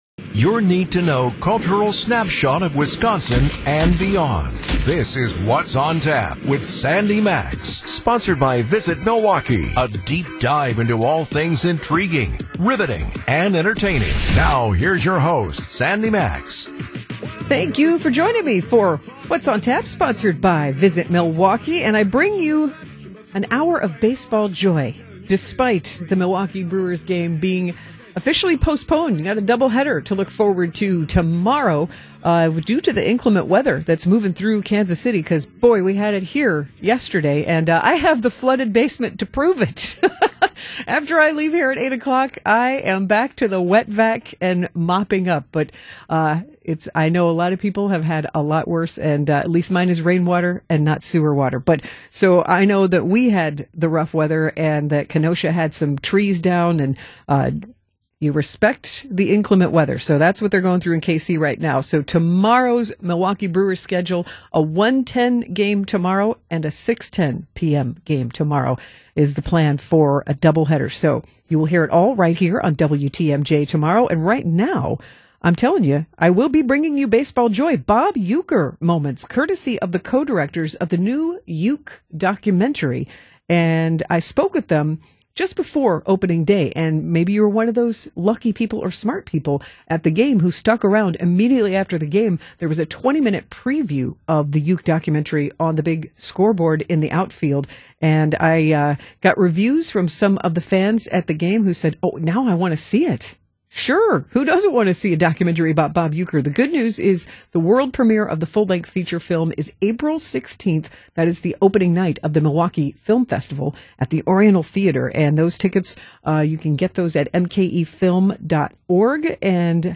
Be a guest on this podcast Language: en Genres: Music , Music Commentary , Music Interviews Contact email: Get it Feed URL: Get it iTunes ID: Get it Get all podcast data Listen Now... Playoff Pocket Paczki, Brewers Buzz, and a Song for the Fans Monday